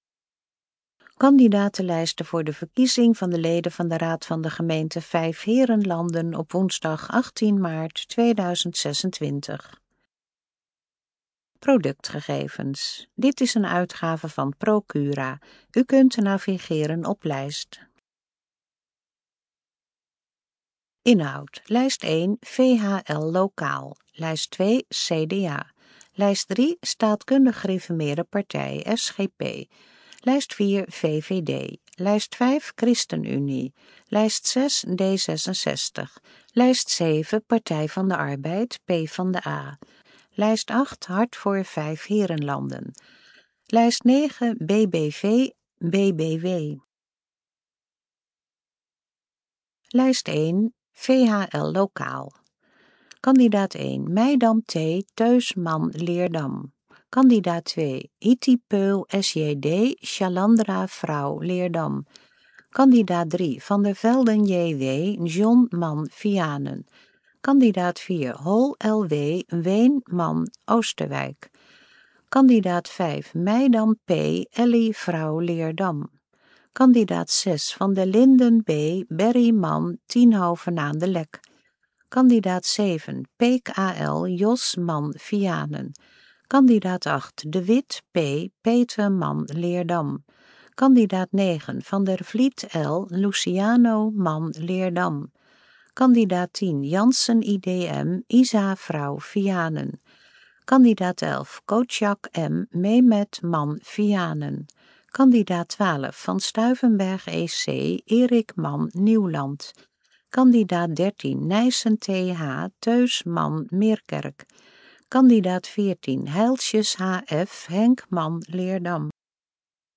Gesproken Kandidaatlijsten Gemeenteraadsverkiezing 2026 Vijfheerenlanden
kandidatenlijst_gesproken-vorm_gr26_vijfheerenlanden.mp3